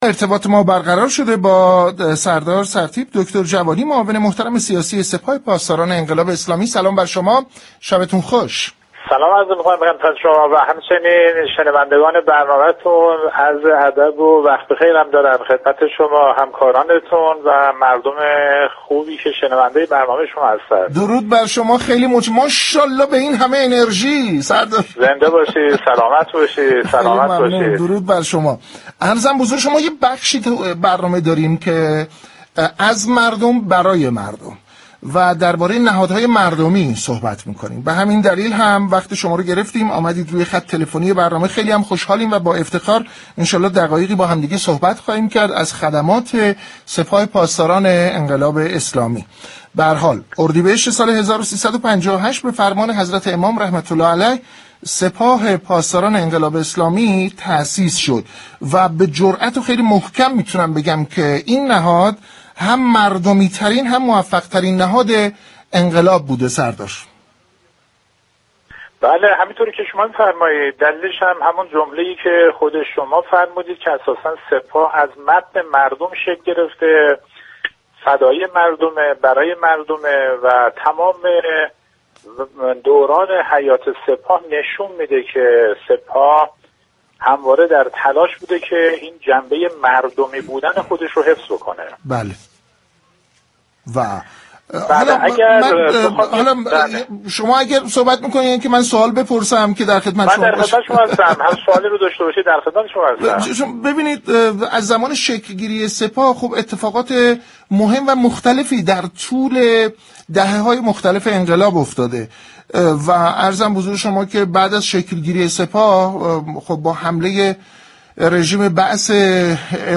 به گزارش پایگاه اطلاع رسانی رادیو تهران، سردار یدالله جوانی معاون سیاسی سپاه پاسداران انقلاب اسلامی در گفت و گو با «اینجا تهران است» اظهار داشت: سپاه پاسداران از متن مردم شكل گرفته است و همواره تلاش كرده جنبه مردمی بودن خود را حفظ كند.